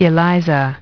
Transcription and pronunciation of the word "eliza" in British and American variants.